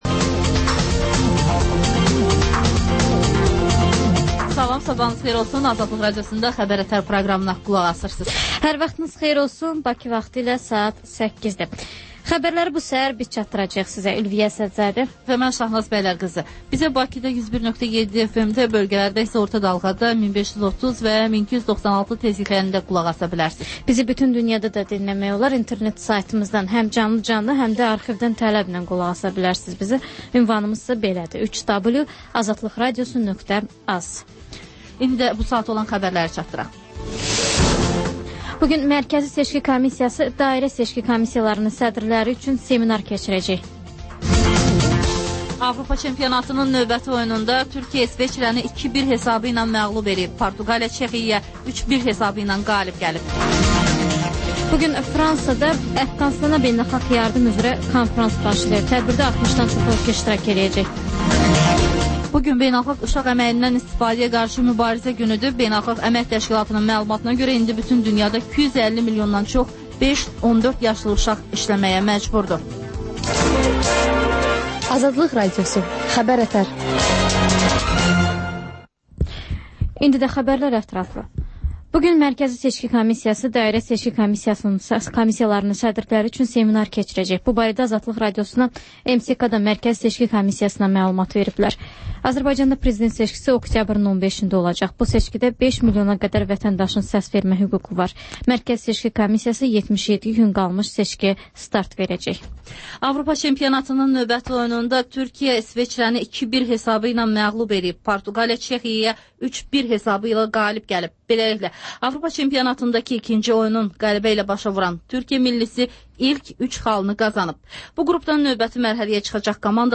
Xəbər-ətər: xəbərlər, müsahibələr, sonda 14-24: Gənclər üçün xüsusi veriliş